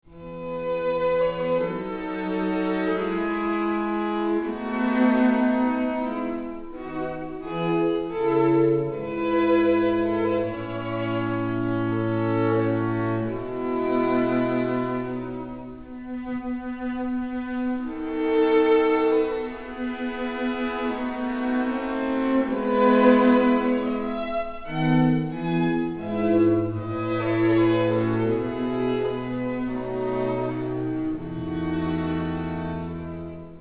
on period instruments
alto
violoncelle)hu